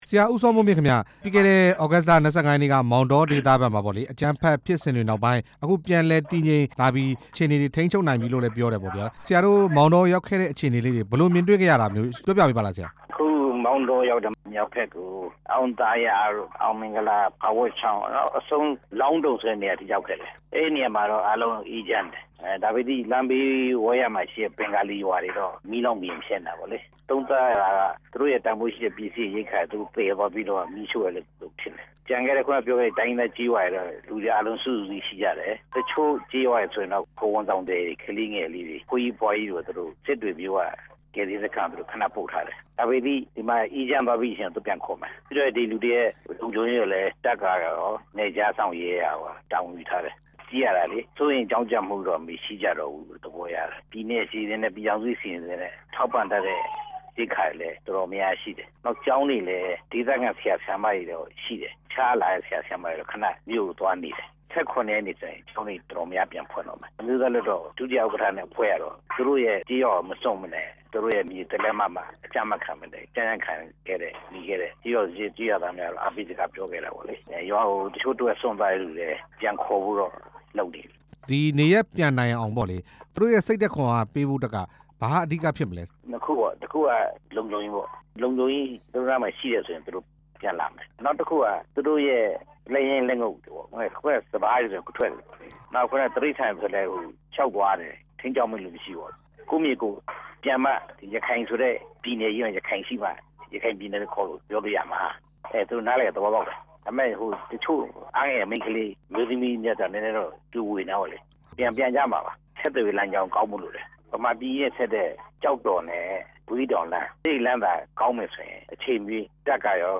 ရခိုင်ပြည်နယ် မောင်တောဒေသကနေ နေရပ်စွန့်ခွာခဲ့ကြသူတွေအနေနဲ့ လုံခြုံရေးစိတ်ချရပြီမို့ ပြန်လည်နေထိုင်နိုင်ပြီလို့ ဒီရက်ပိုင်းအတွင်း မောင်တောမြို့ကို ကွင်းဆင်းလေ့လာခဲ့တဲ့ အမျိုးသားလွှတ်တော် ကိုယ်စားလှယ် ဦးစောမိုးမြင့်က ပြောပါတယ်။